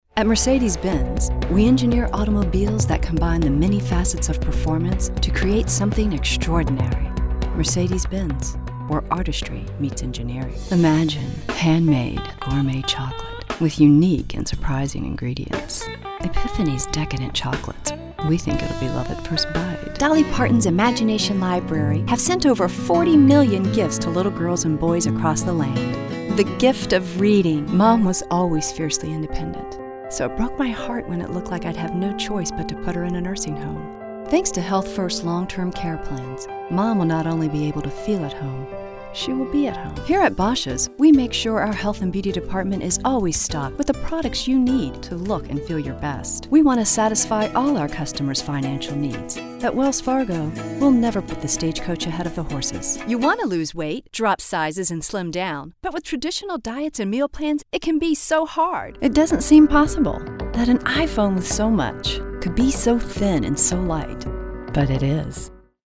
Commercial Voice Over Demo Reel